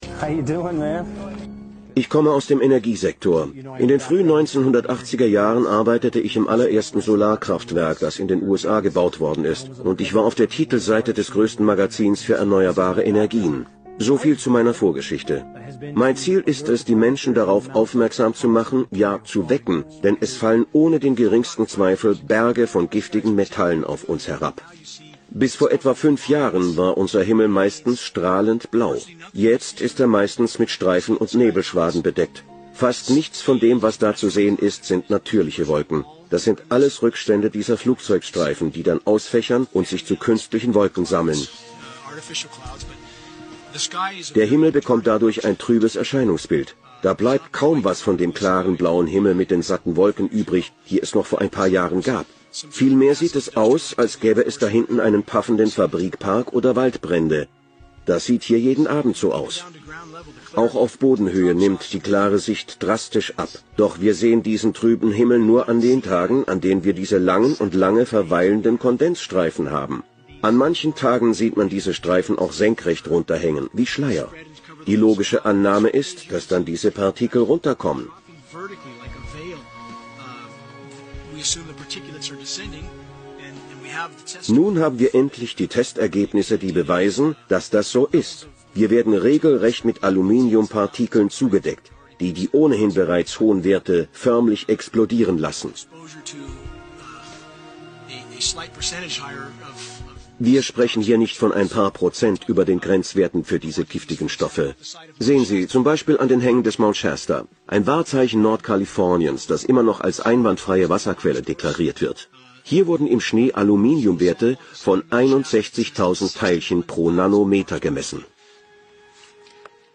Diese Doku hat schon ein paar Jahre auf dem Buckel, und doch ist sie aktueller den je! Hier kommen namhafte Wissenschaftler zu Wort, die aufmerksam ihre Umwelt beobachtet und gemessen haben und es ist ganz klar festzustellen, dass schon damals Tonnen an giftigen Schwermetalen auf uns herniederprasseln, welche gerne im Rahmen des Geoengineering zur Wetterbeeinflussung eingesetzt werden - damals steckte man diesbezüglich in dieser Forschungsdisziplin noch in den Kinderschuhen, heute ist man da sicherlich um einiges weiter, was die Situation betreffend Umweltgift sicherlich nicht verbessert hat..